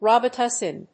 /ˌrɑbɪˈtʌsɪn(米国英語), ˌrɑ:bɪˈtʌsɪn(英国英語)/